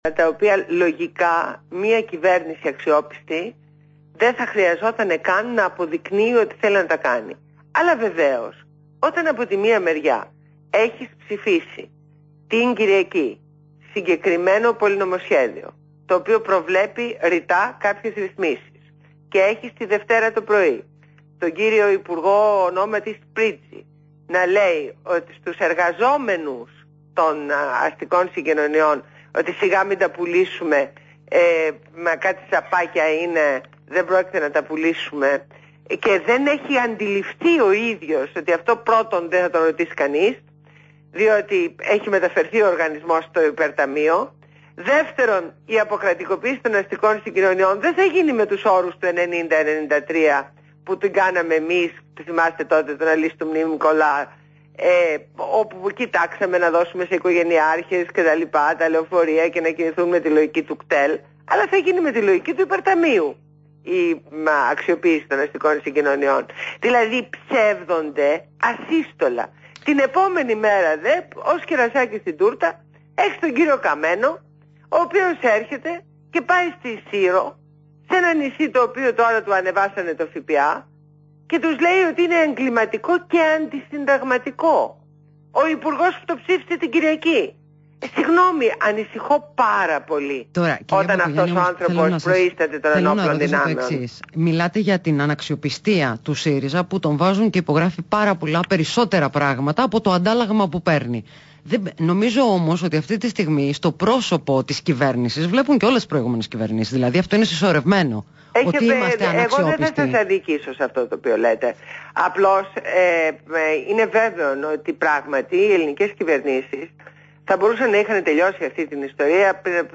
Συνέντευξη στο ραδιόφωνο του REALfm